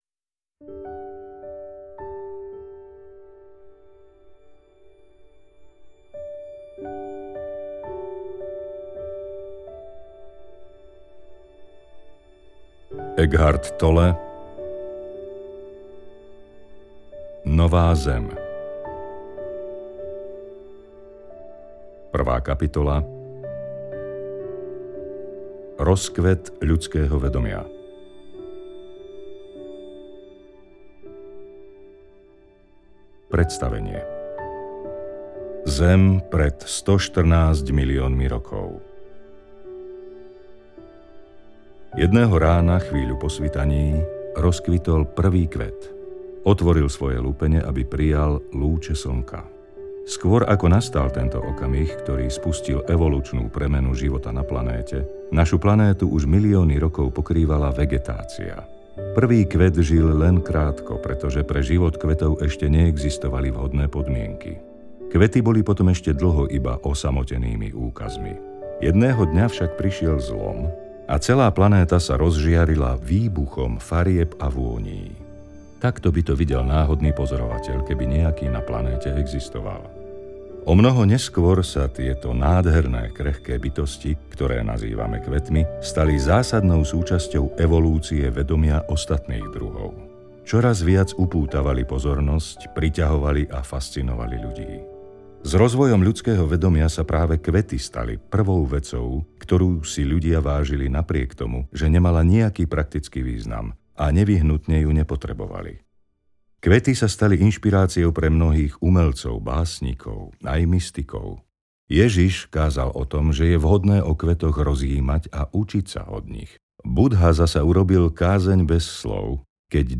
Nová Zem audiokniha
Ukázka z knihy